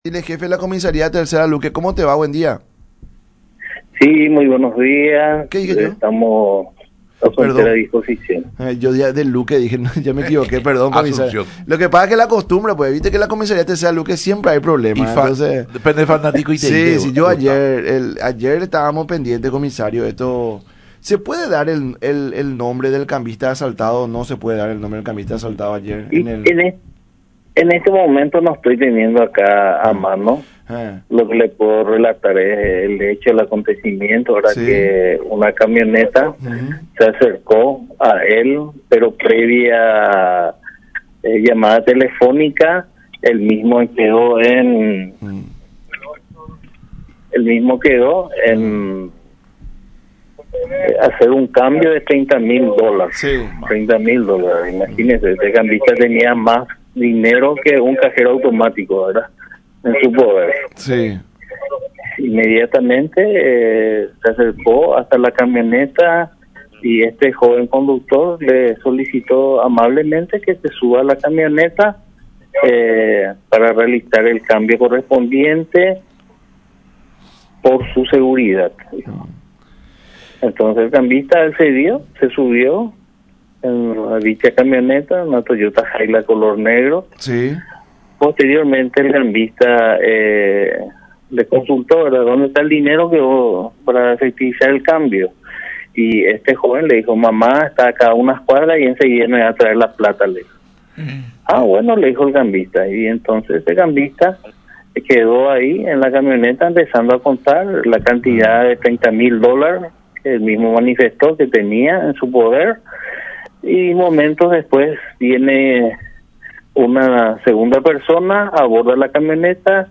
“El asaltado al ser consultado en la comisaria, manifestó que es su cliente. El joven ya fue detenido en horas de la noche y está a disposición de la fiscalía”, agregó en el programa “La Mañana De Unión” por radio La Unión y Unión TV.